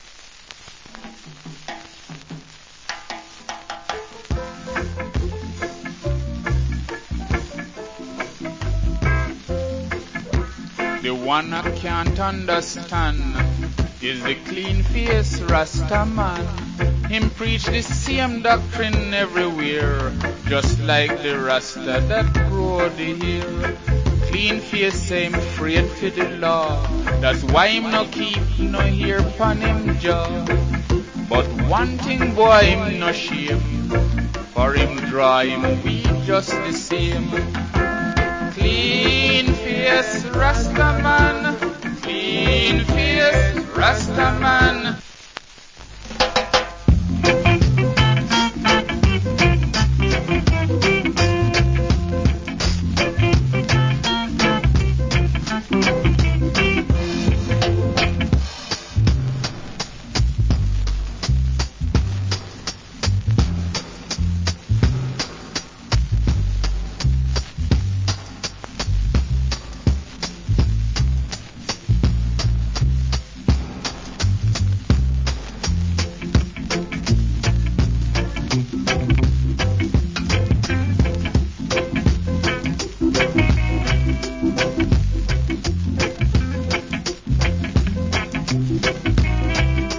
Good Roots Rock Vocal.